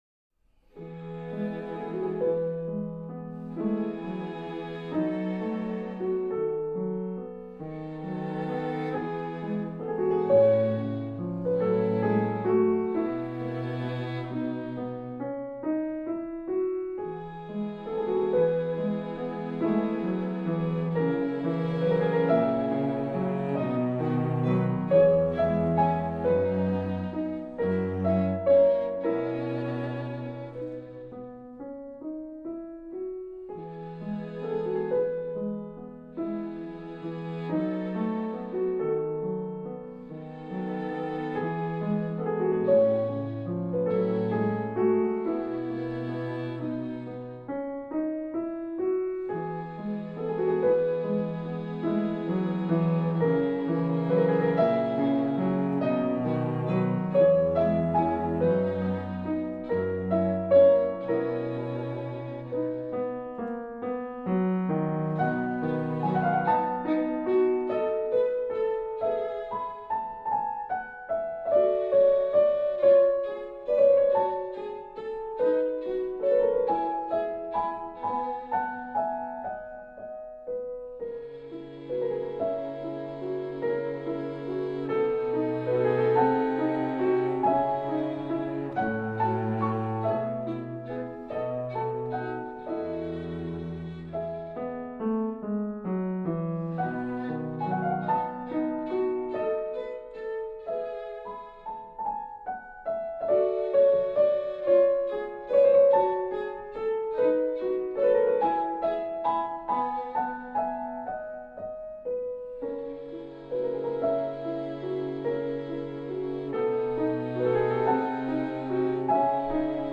скрипка
виолончель
фортепиано